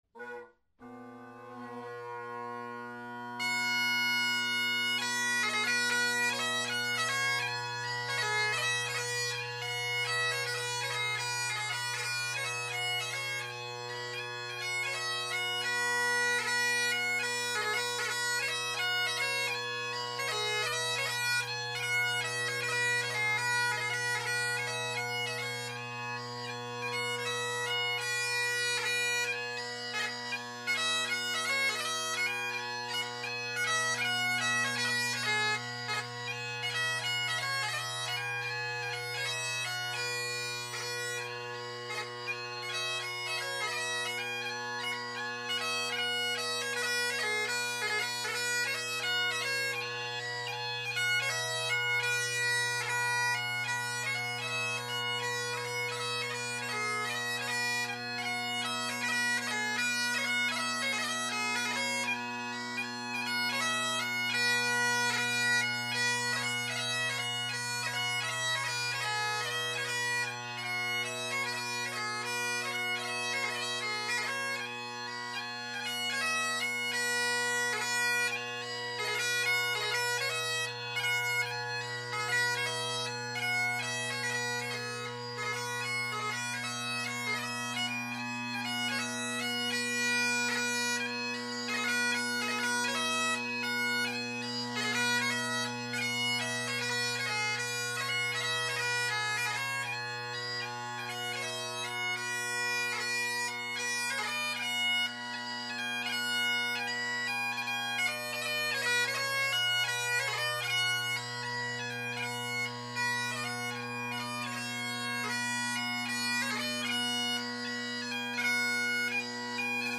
Great Highland Bagpipe Solo
We start with some more quiet recordings with my Naill-spec Rockets.
The bag these are tied into kinks right above the chanter stock which I find irritating and think contributed to the chanter sound, especially the rather odd sounding high G. None of these tunes are played particularly well as the geometry of this pipe as it’s tied in is just wrong for me.